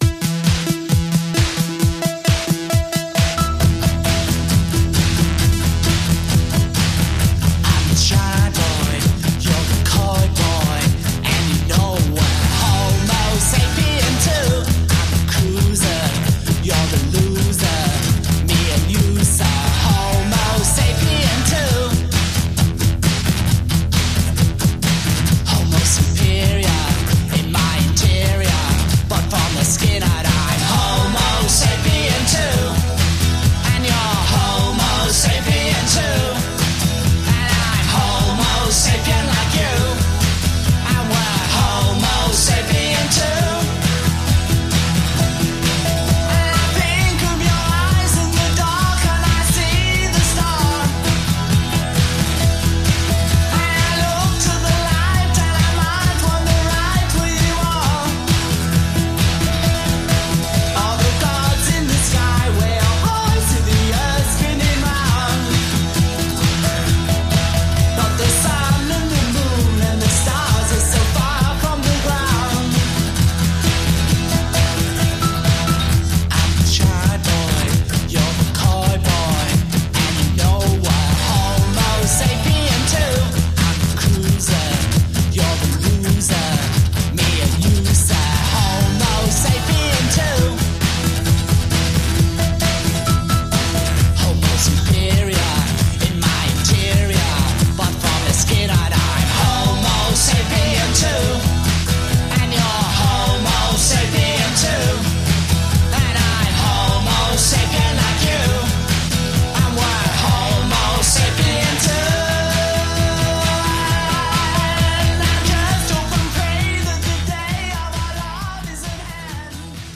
BPM132-132
Audio QualityCut From Video